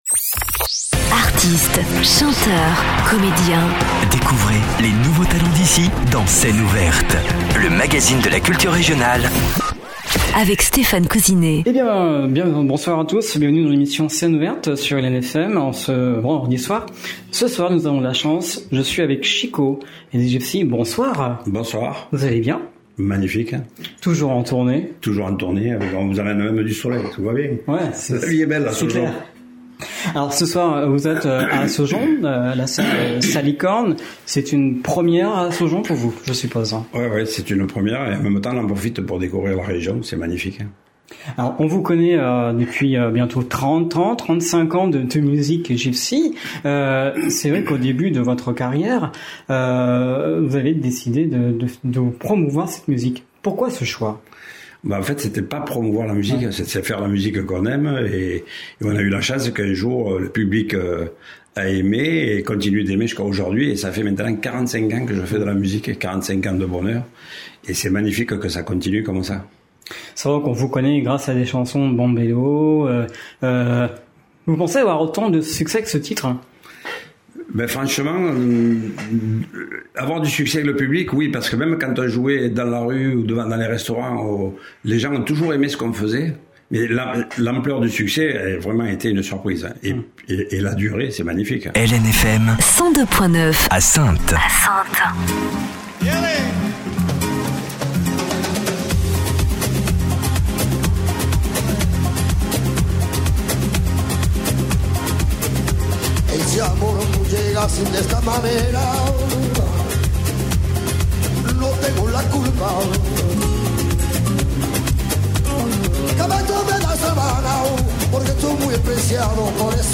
Scène ouverte du 28/11/2025 avec Chico and the Gypsies, groupe français de rumba catalane, flamenco, pop et rock, dirigé par Chico Bouchikhi, un ancien membre des Gipsy Kings.